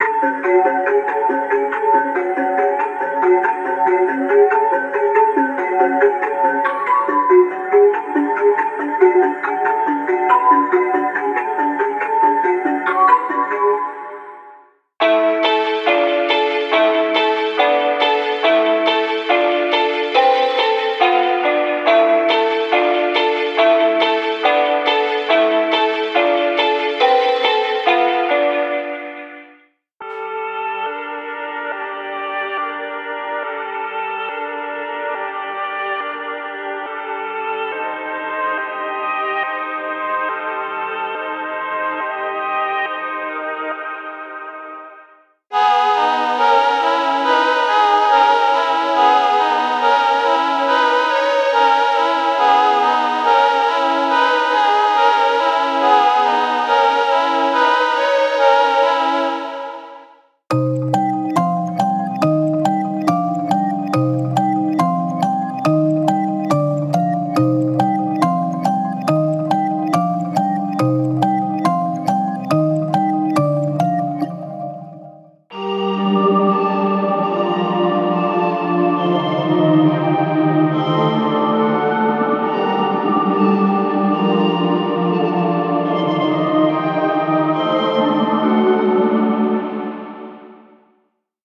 MIDI Melodies Demo